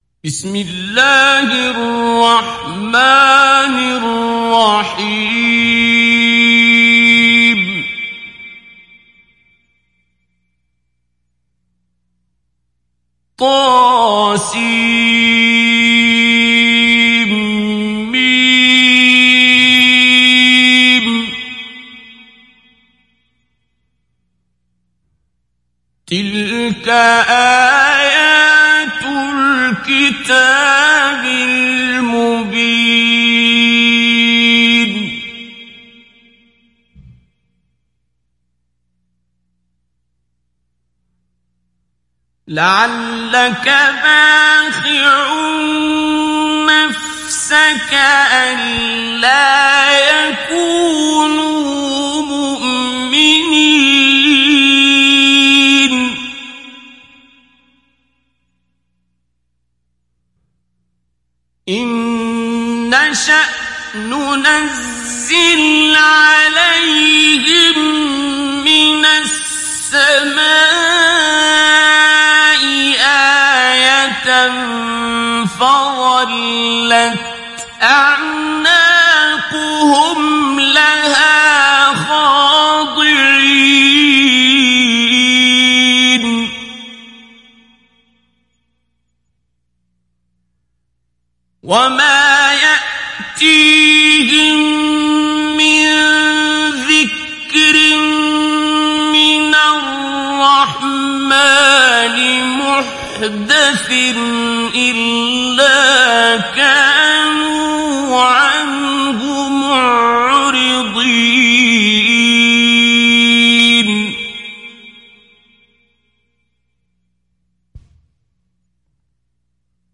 Sourate Ash Shuara Télécharger mp3 Abdul Basit Abd Alsamad Mujawwad Riwayat Hafs an Assim, Téléchargez le Coran et écoutez les liens directs complets mp3
Télécharger Sourate Ash Shuara Abdul Basit Abd Alsamad Mujawwad